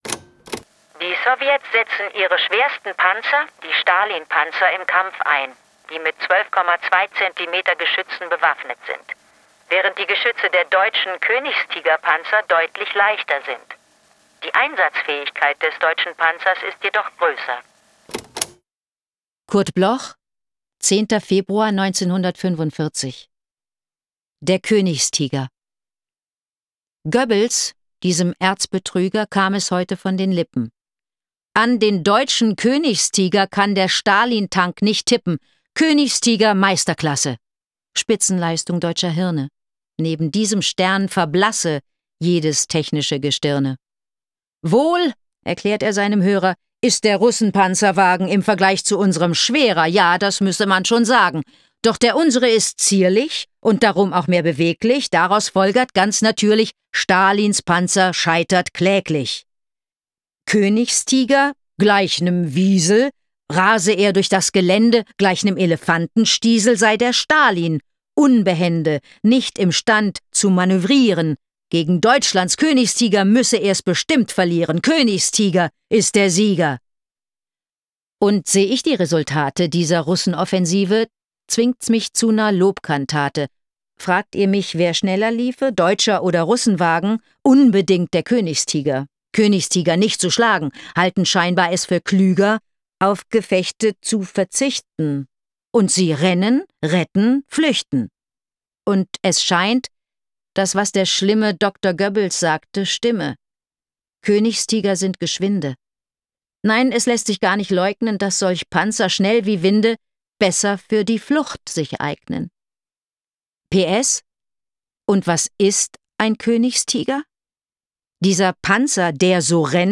vorgetragen von Anke Engelke
Anke-Engelke-Der-Koenigstiger.m4a